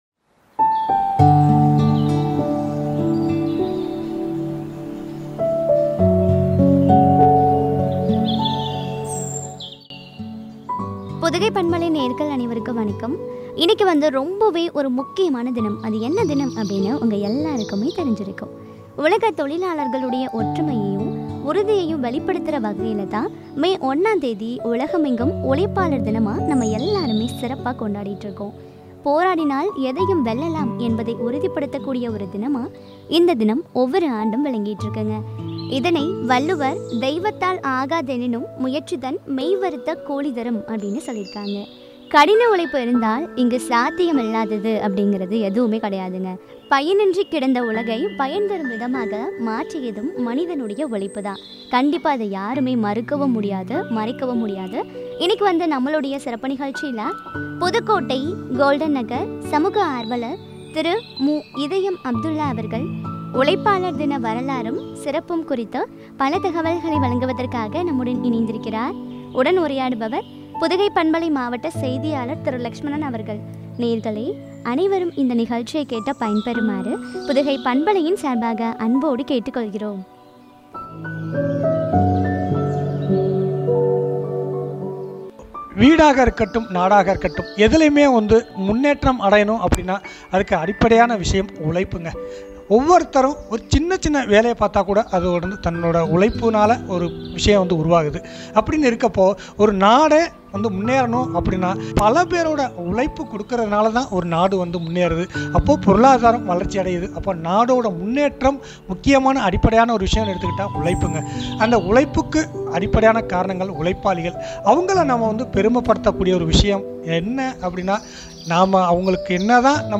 சிறப்பும் பற்றிய உரையாடல்.